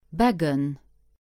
English: Pronunciation of the word "Beggen" in Luxembourgish. Female voice.